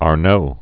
(är-nō), Antoine 1612-1694.